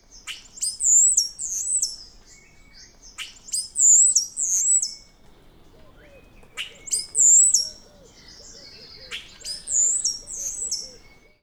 Star Gesang
• Stare können Geräusche anderer Vögel und sogar menschliche Geräusche imitieren.
• Ihr Gesang ist abwechslungsreich und laut.
Star-Gesang-Voegel-in-Europa.wav